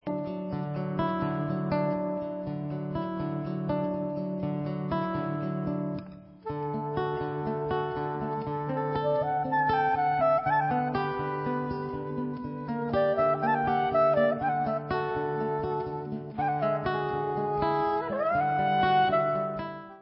LIVE IN BRUSSELS